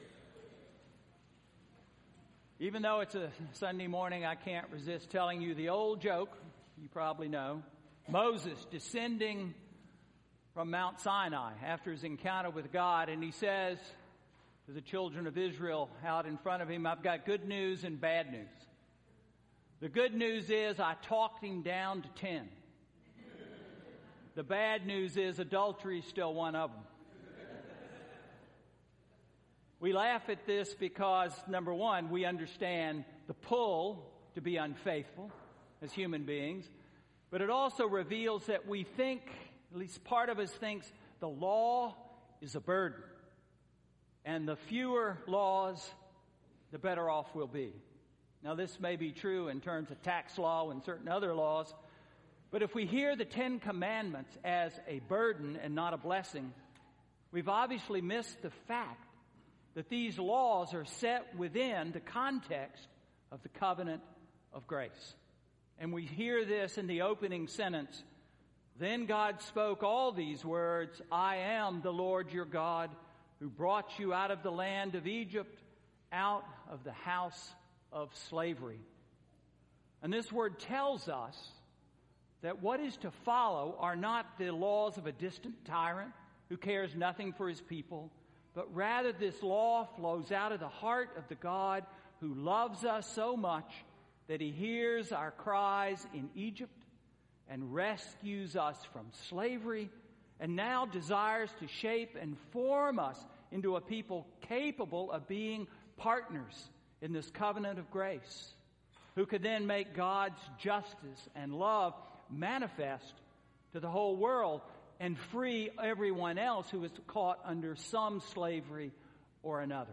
Sermon–Lent 3–March 8, 2015